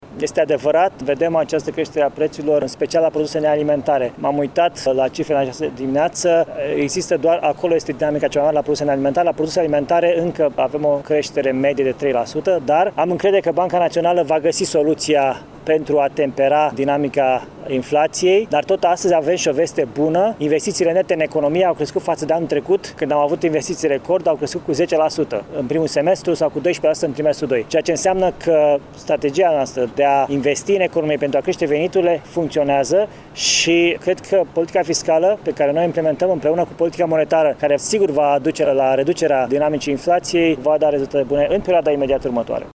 În conferinţa de presă care a urmat întâlnirii cu liberalii ieşeni, Florin Cîţu s-a referit, între altele, la creşterile de preţuri constatate în ultima perioadă.